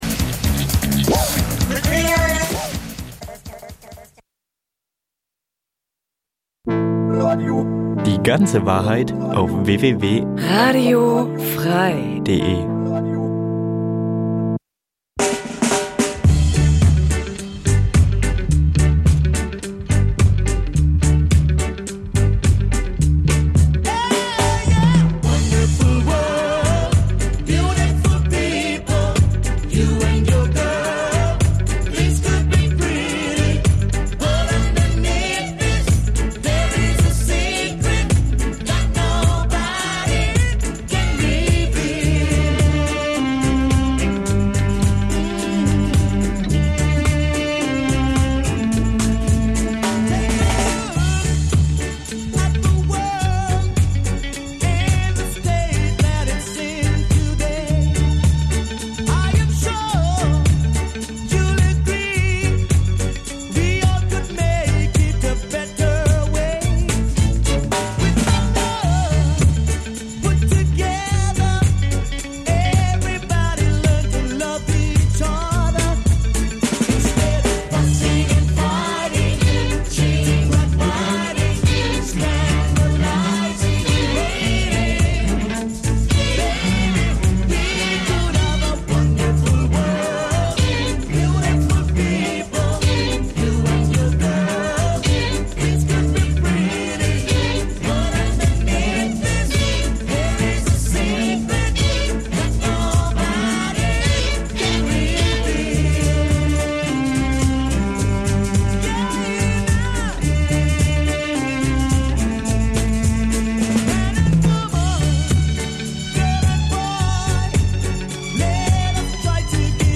Reggae, Ska, Dub Dein Browser kann kein HTML5-Audio.
Reggae, Ska, Dub von alt bis neu.